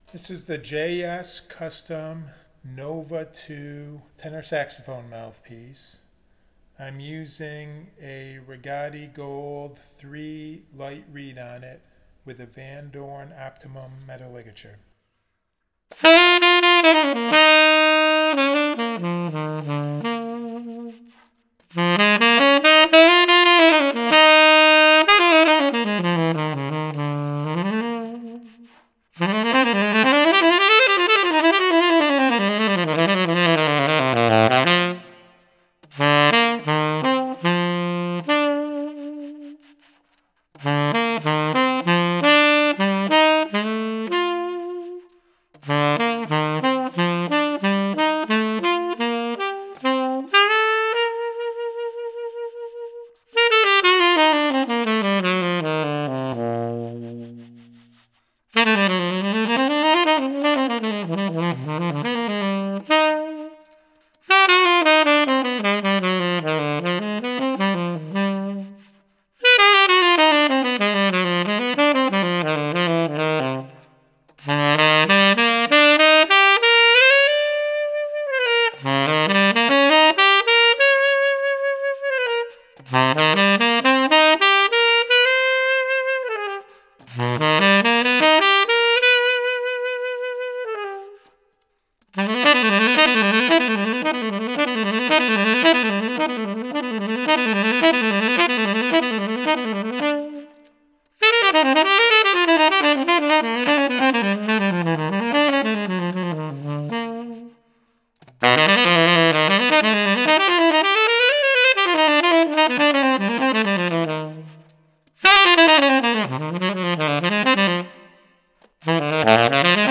The first sound clip is a long 4′ 30″ clip recorded with reverb added on a Rigotti Gold 3 Light tenor saxophone reed and Vandoren Optimum tenor saxophone ligature.
I feel like the reverb gives more fatness and roundness to the bright overtones, sizzle and sparkle in the tone.
In my opinion, the JS Custom Nova 7* tenor saxophone mouthpiece is a unique and quite interesting budget friendly tenor saxophone mouthpiece for tenor sax players looking for a powerfully concentrated and focused tenor sax sound that has some beautiful bright overtones added to the tone.
JS Custom Nova 7* Tenor Saxophone Mouthpiece – Reverb Added – Rigotti Gold 3 Light Reed